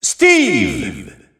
The announcer saying Steve's name in French.
Steve_French_Announcer_SSBU.wav